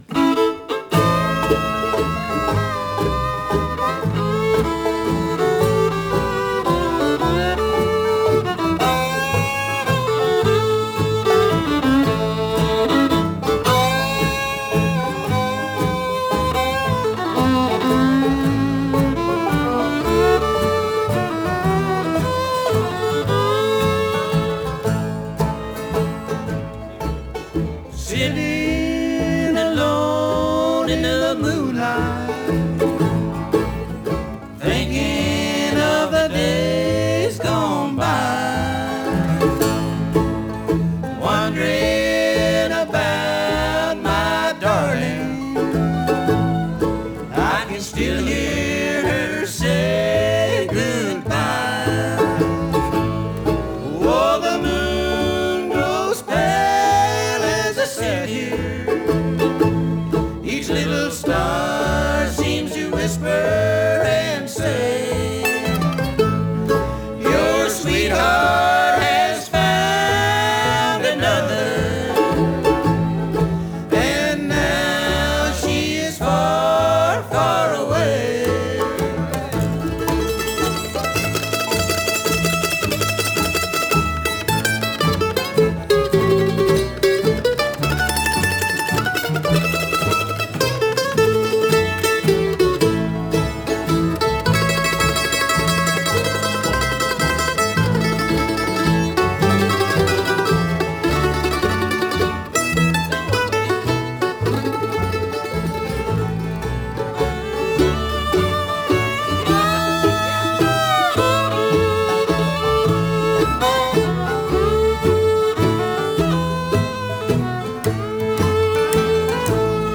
vocals, guitar
mandolin, vocals
banjo
fiddle, vocal
California for a sold-out concert.
captured (with one microphone)